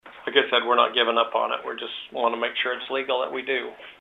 (Adair Co) The Adair County Board of Supervisors held another public hearing this (Wednesday) morning on a proposed ordinance regulating the placement of large scale commercial and industrial buildings.